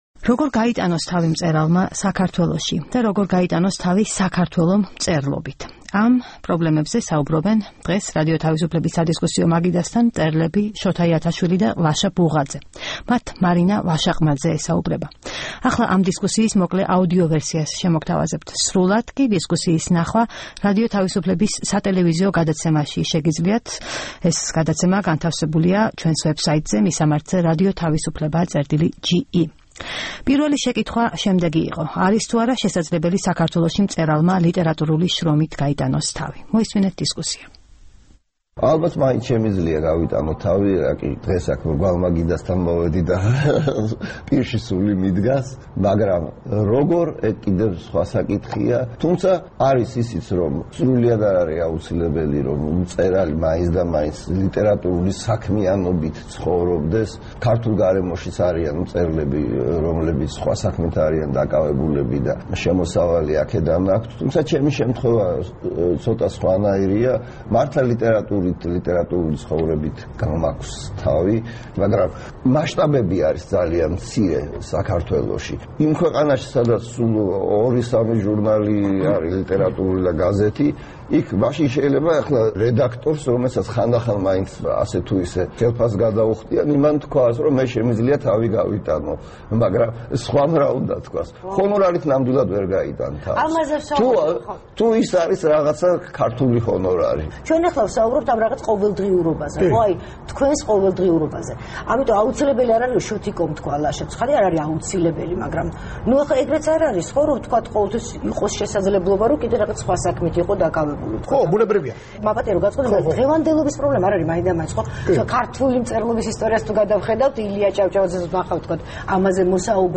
როგორ გაიტანოს თავი მწერალმა საქართველოში? და როგორ გაიტანოს თავი საქართველომ მწერლობით? ამ პრობლემებზე საუბრობენ დღეს რადიო თავისუფლების სადისკუსიო მაგიდასთან მწერლები შოთა იათაშვილი და ლაშა ბუღაძე.